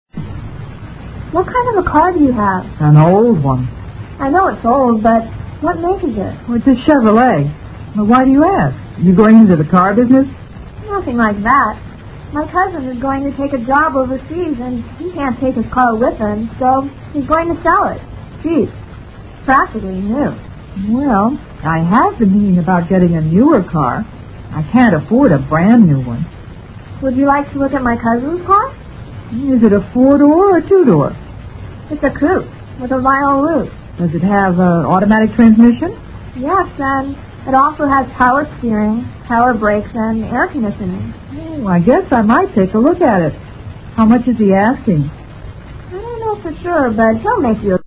Dialogue 4